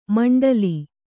♪ maṇḍali